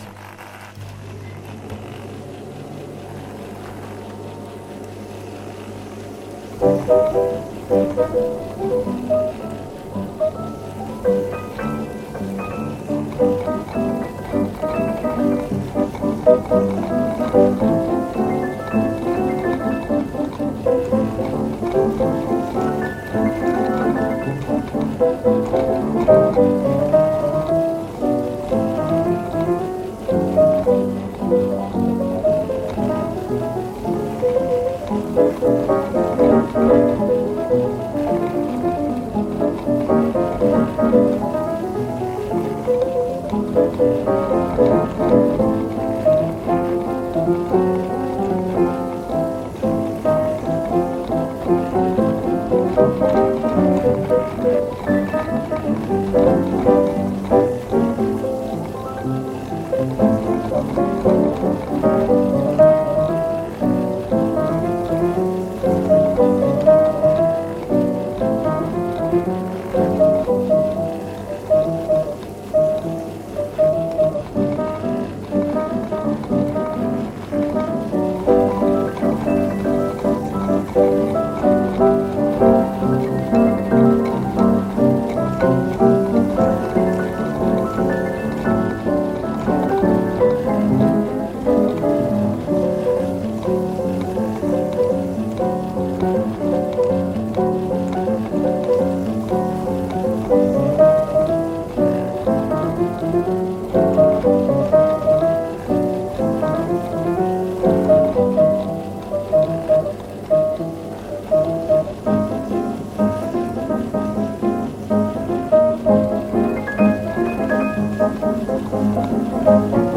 Piano électropneumatique
Piano électronique-automatique Wurlitzer
La grande quantité et la diversité des rouleaux que le musée conserve permet de survoler un grand champ stylistique du classique aux années 80.